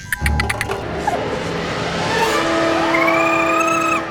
File:Baby Notzilla roar.ogg
Baby_Notzilla_roar.ogg